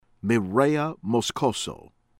MARTINELLI, RICARDO rih-KAHR-doh    mahr-tih-NEH-lee